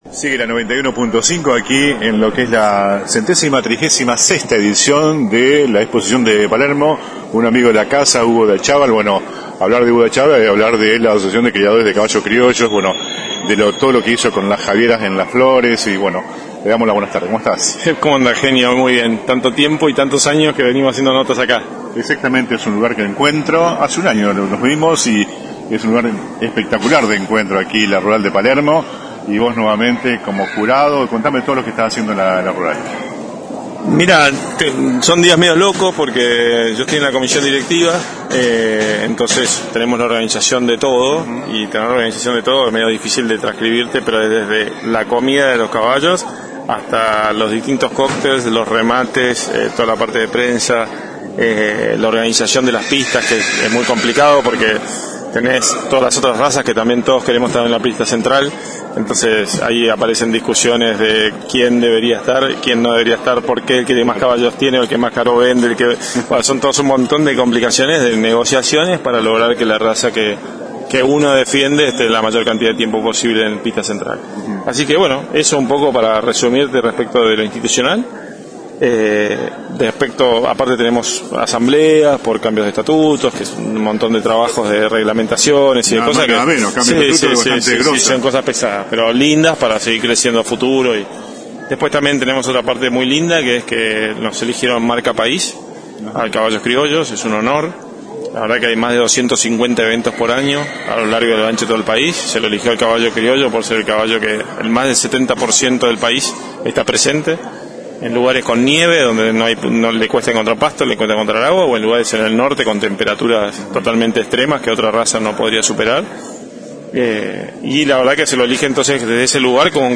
(galería de imágenes) La 91.5 dice presente una vez más en la mayor muestra del campo del país.